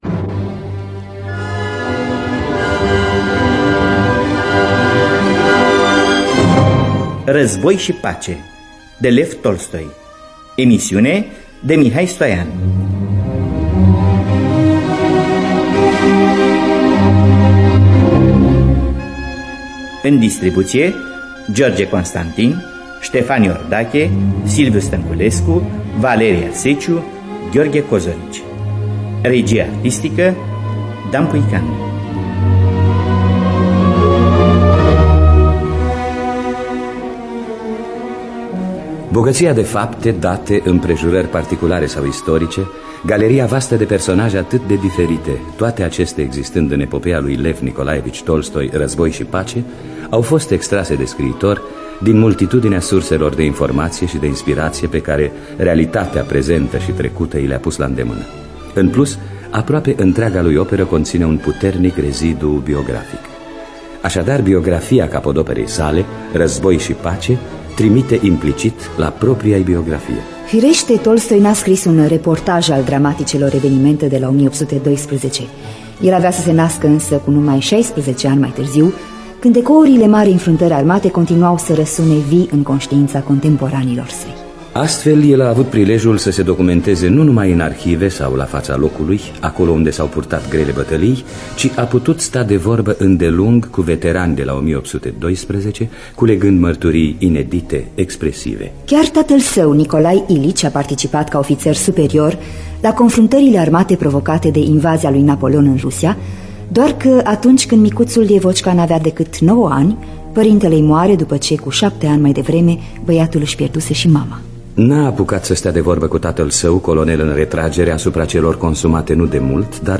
Scenariu radiofonic